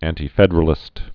(ăntē-fĕdər-ə-lĭst, -fĕdrə-lĭst, ăntī-)